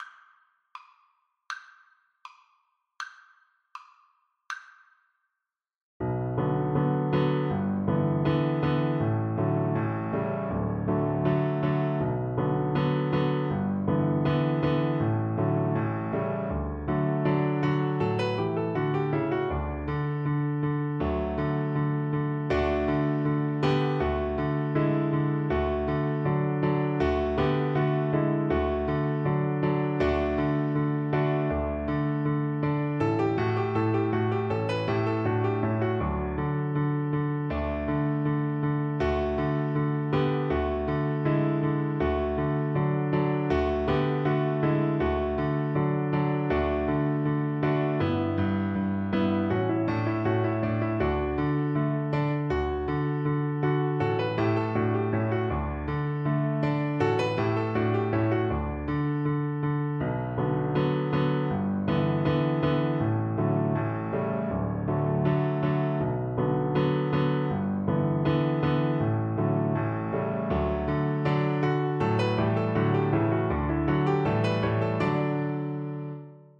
Cello
D major (Sounding Pitch) (View more D major Music for Cello )
2/4 (View more 2/4 Music)
Allegro (View more music marked Allegro)
Classical (View more Classical Cello Music)